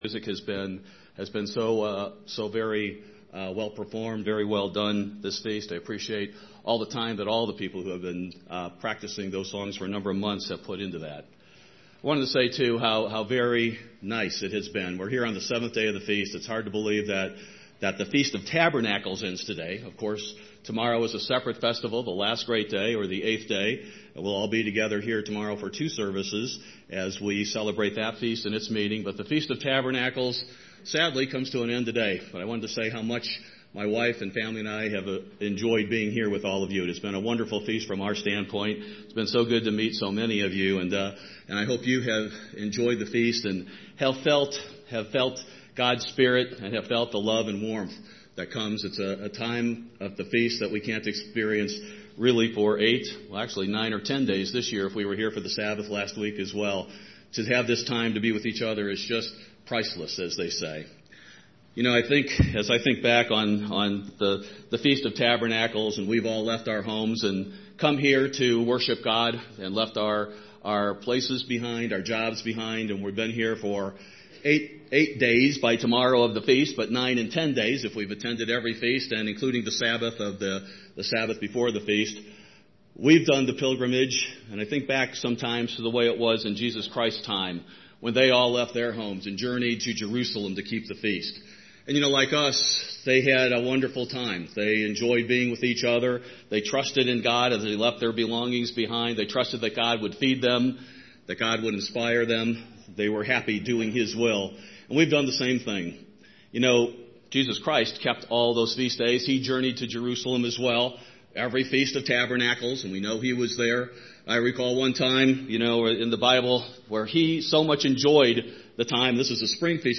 This sermon was given at the Steamboat Springs, Colorado 2016 Feast site.